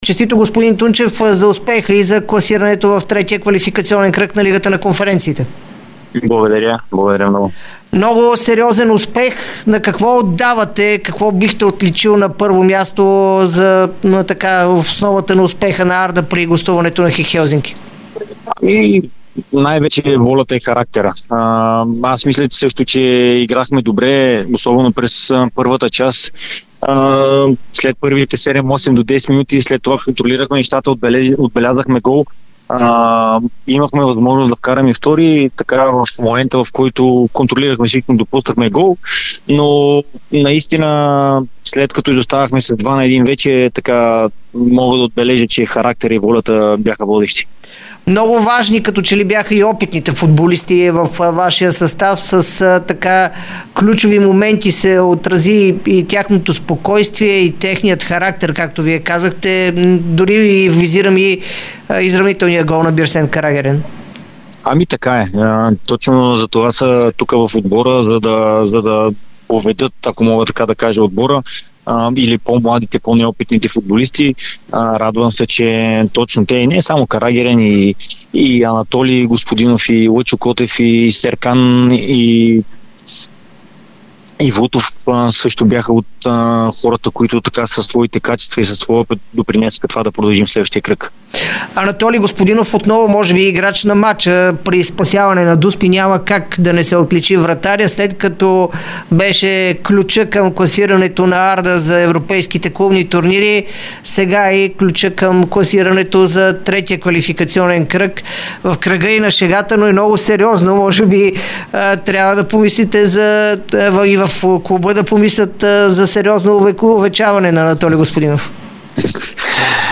Старши треньорът на Арда - Александър Тунчев, говори пред Дарик и dsport след отстраняването на ХИК Хелзинки, отличавайки водещите компоненти за успеха на кърджалийци.